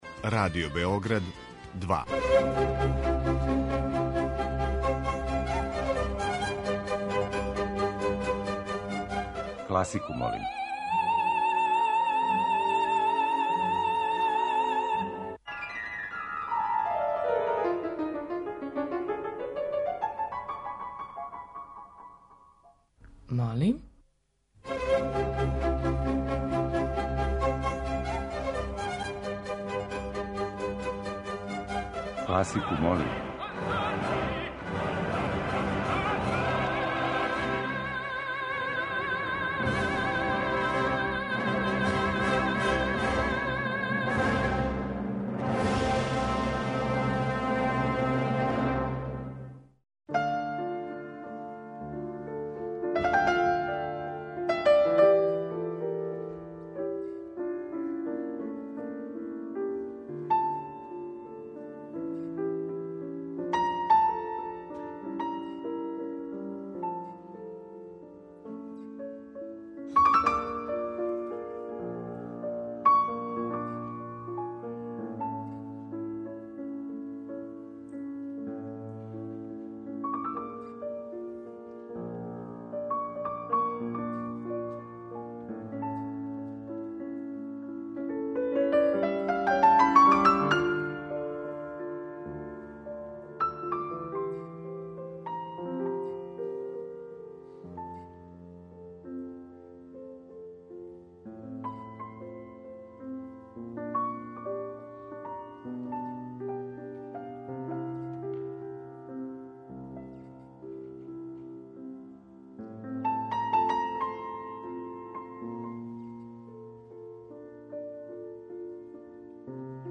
Прве симфоније неких најпознатијих композитора